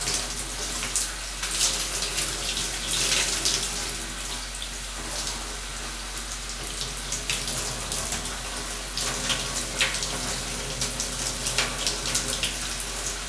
water_running_shower_dripping_loop_02.wav